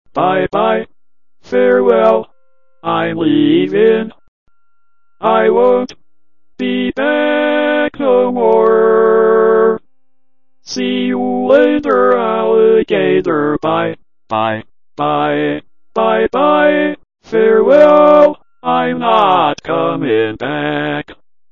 Even with its limited inflection, it was sobbing! It also yodled!